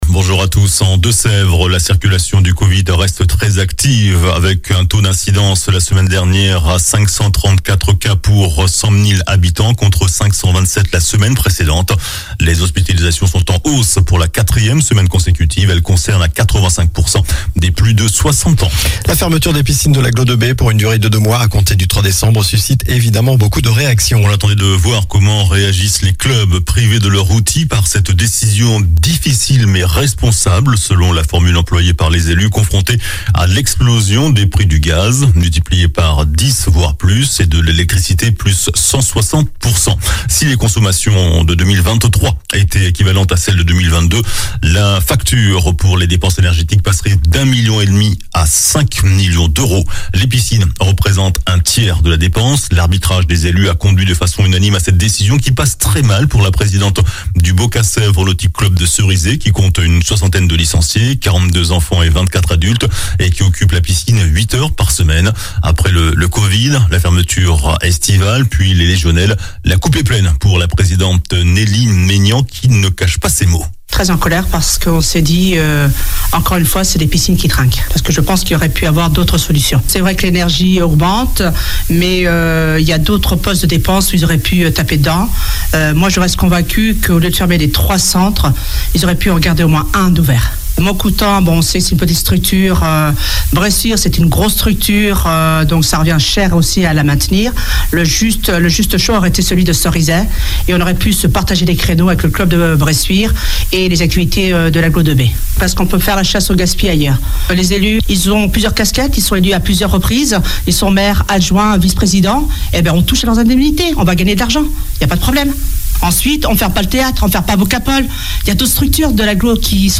JOURNAL DU SAMEDI 22 OCTOBRE